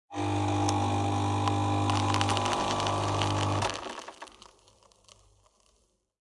办公室的咖啡机
描述：在我工作的办公室里，咖啡机在冲泡一杯普通咖啡的30秒内发出了很大的噪音。这段录音是在2009年1月的一个清晨用我的H2录制的。
标签： 咖啡机 噪音
声道立体声